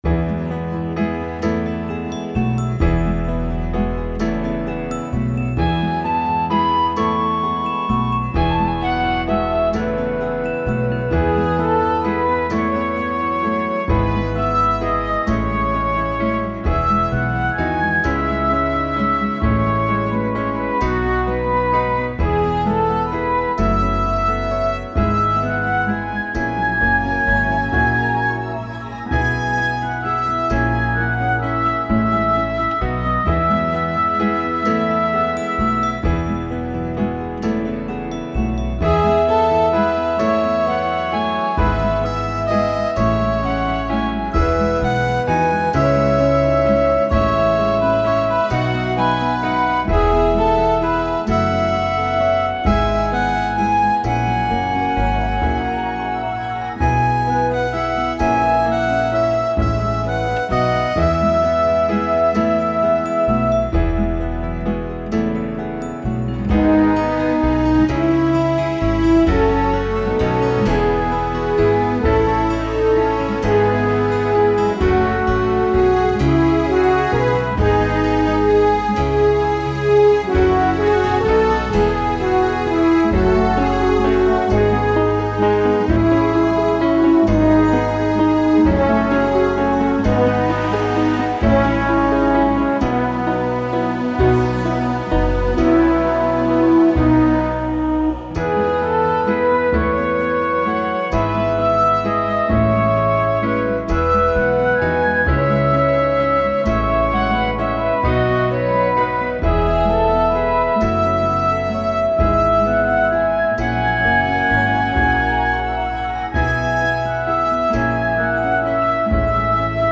Initially, I worked with an arranger to create these lush arrangements.
5-clear-instrumental.mp3